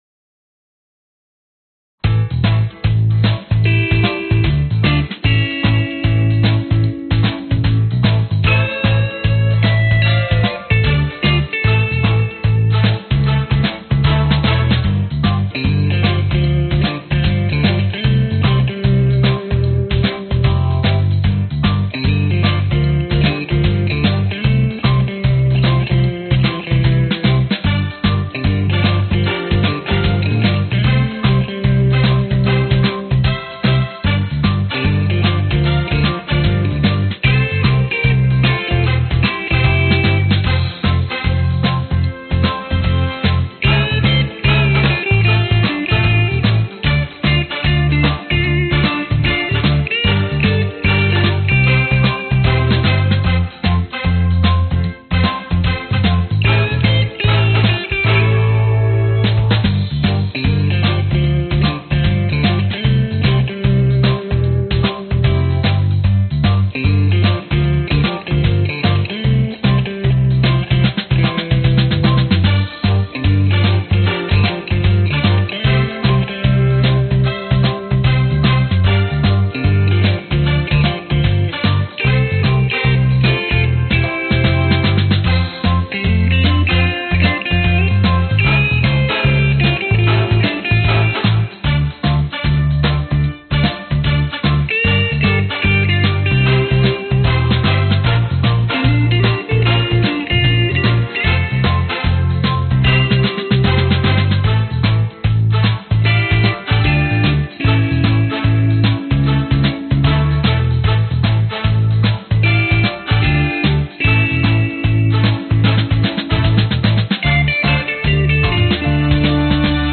标签： bass brass drums guitar piano soul
声道立体声